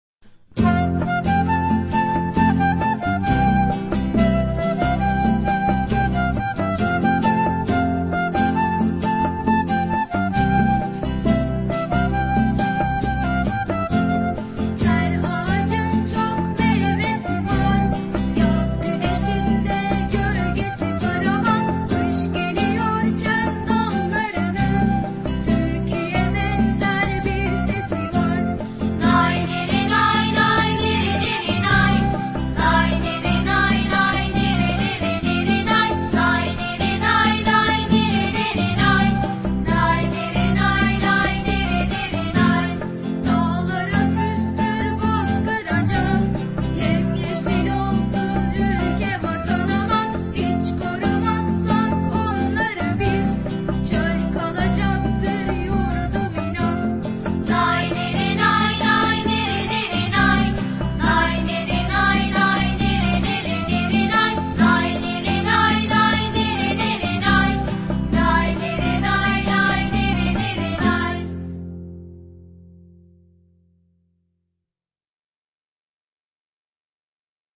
sözlü